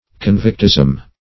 Search Result for " convictism" : The Collaborative International Dictionary of English v.0.48: Convictism \Con"vict*ism\, n. The policy or practice of transporting convicts to penal settlements.